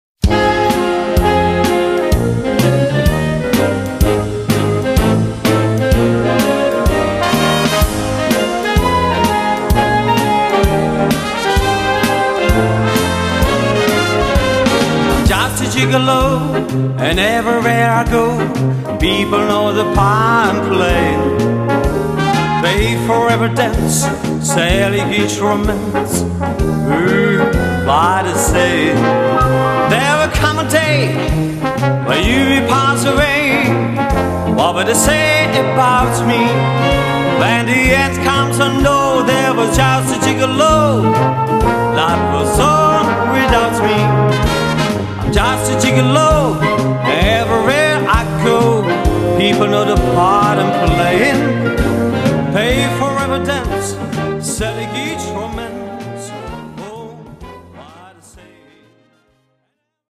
Gattung: Solo für diverse Instumente und Blasorchester
Besetzung: Blasorchester
Solo: Männer-Gesangsstimme oder Tenorsax oder Posaunensolo.